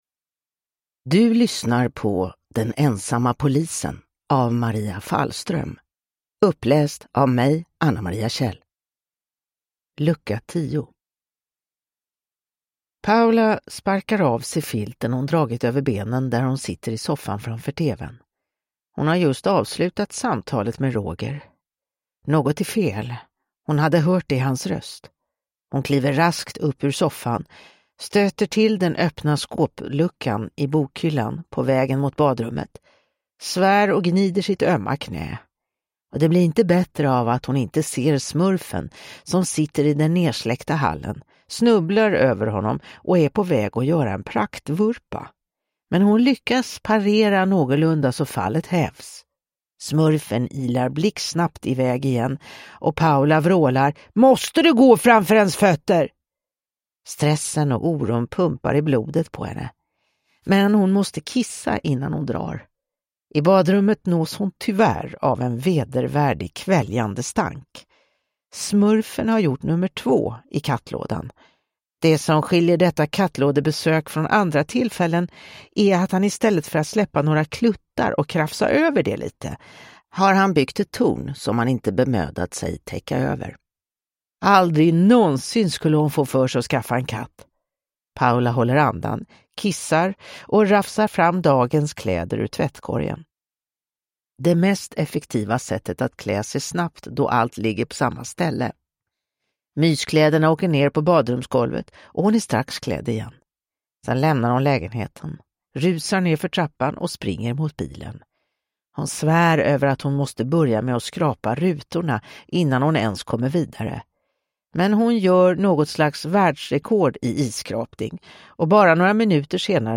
Den ensamma polisen: Lucka 10 – Ljudbok